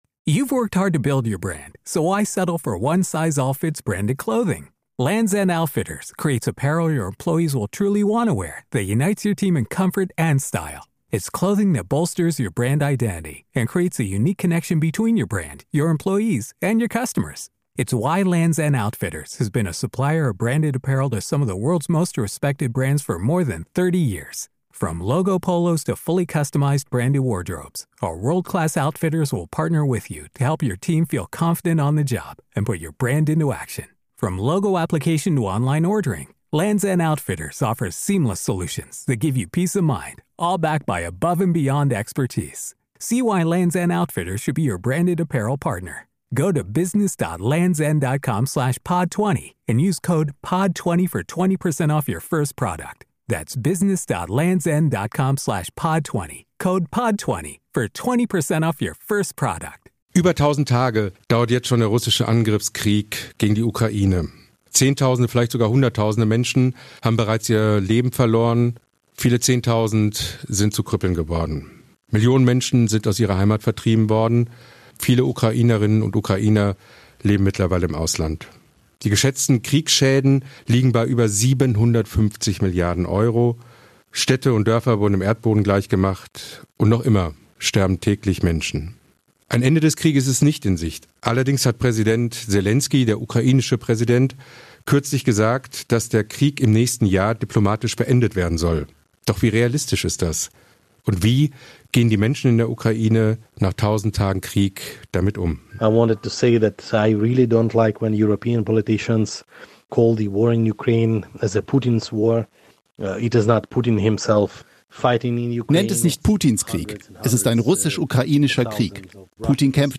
mit Soldaten an der Front, Bewohnern zerstörter Dörfer und Familien in Kiew über ihre Realität nach 1000 Tagen Krieg.